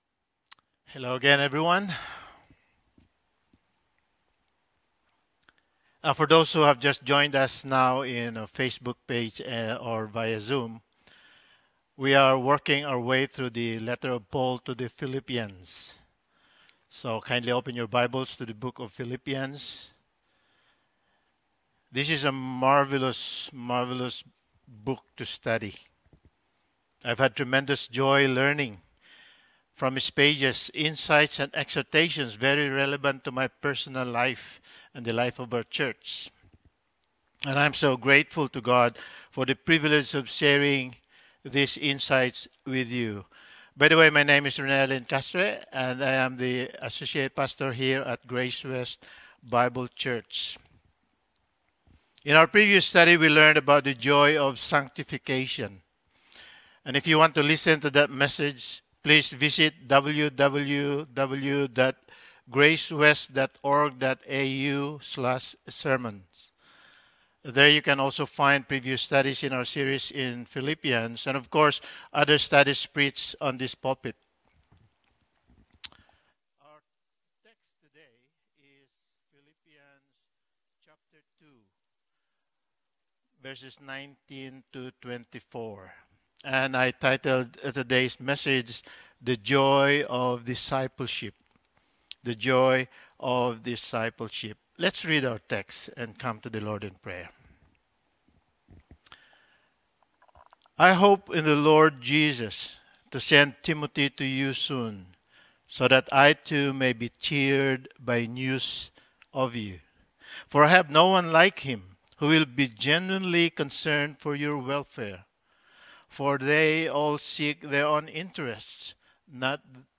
Passage: Philippians 2:19-24 Service Type: Sunday Morning